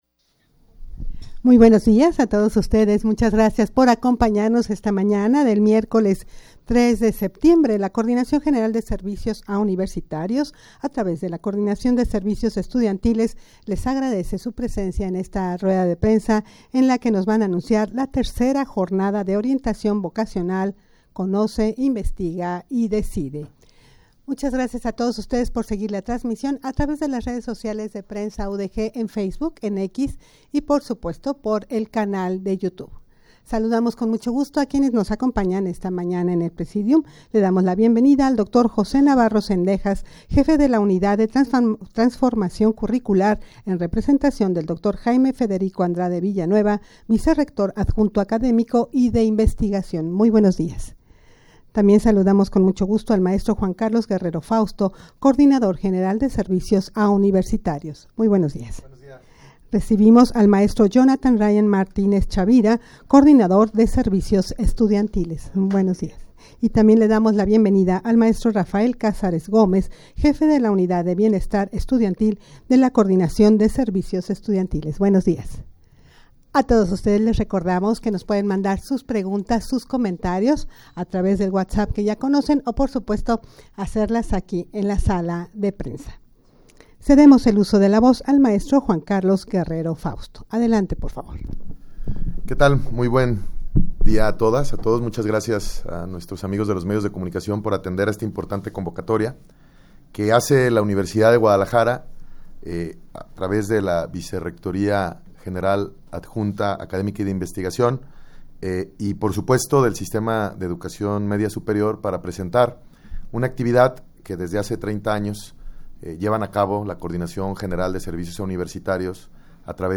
Audio de la Rueda de Prensa
rueda-de-prensa-para-anunciar-la-iii-jornada-de-orientacion-vocacional-cid-conoce-investiga-y-decide.mp3